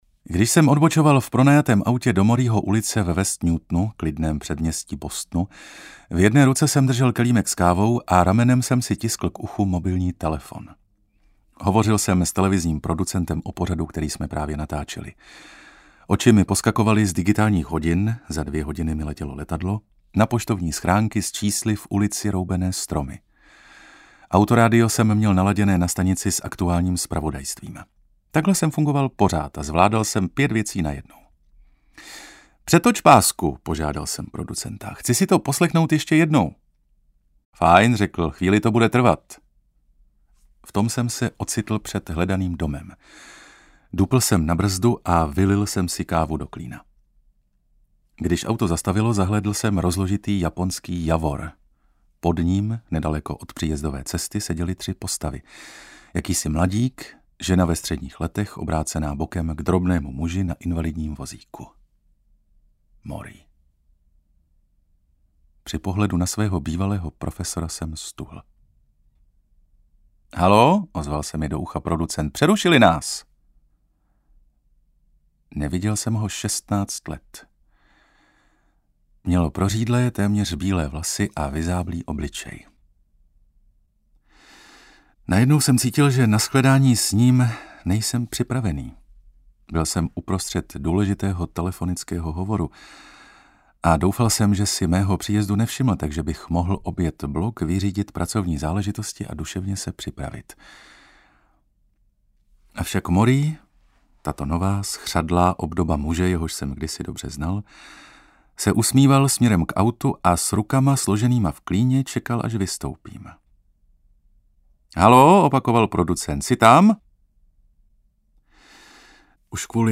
Ukázka z knihy
uterky-s-morriem-aneb-posledni-lekce-meho-ucitele-audiokniha